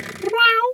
cat_2_meow_01.wav